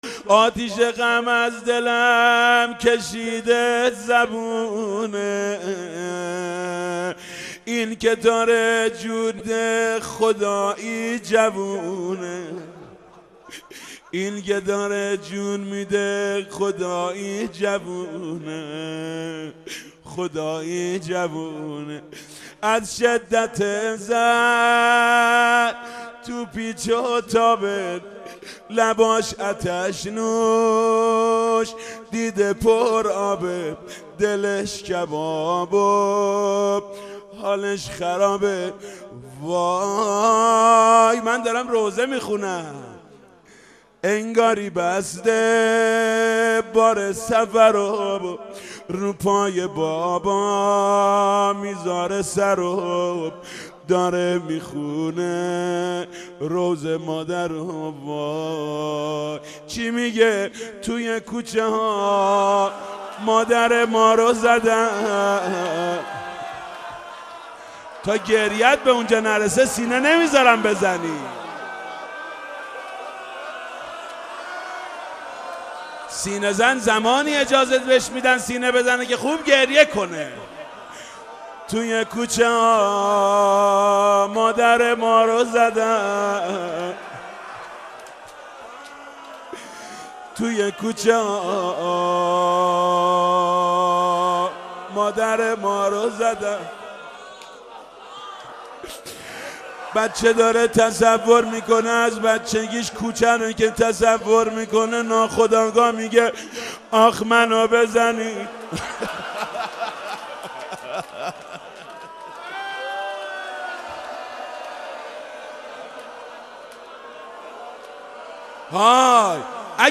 روضه: این که داره جون میده خدایی جوونه